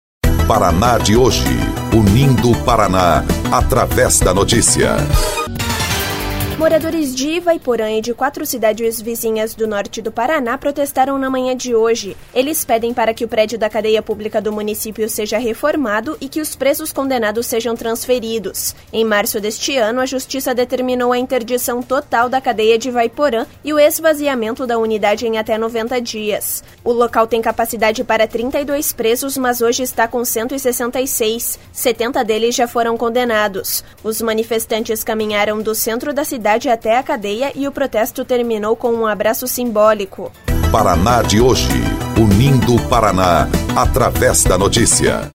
BOLETIM – População protesta por reforma de cadeia em Ivaiporã e região